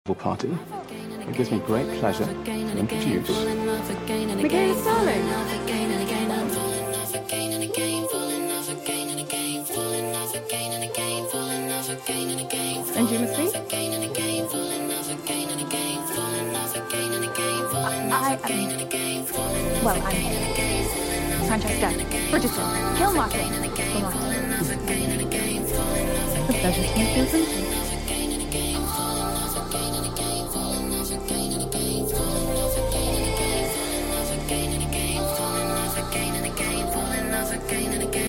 Sorry the quality is a bit sh*t 🥴